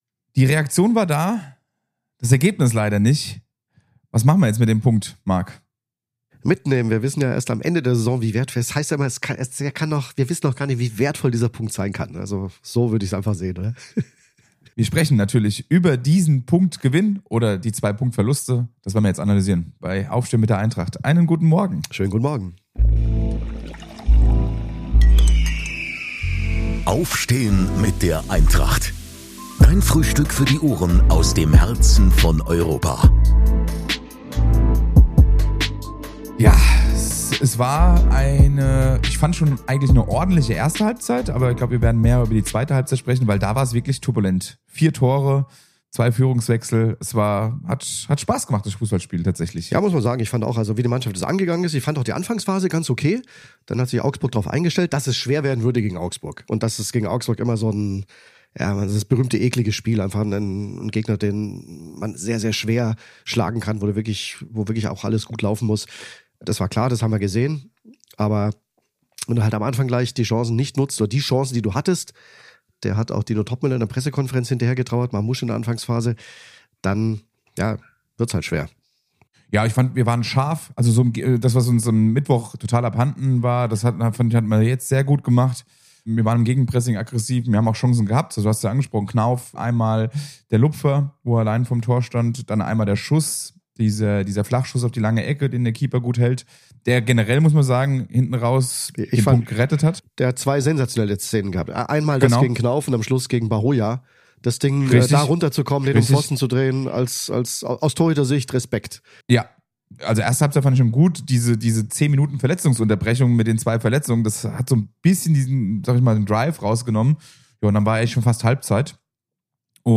Als Gast spricht Nationalspielerin Sarah Doorsoun über das heutige Spiel der Eintracht Frauen gegen Leipzig und die Chancen – Herbstmeisterin zu werden.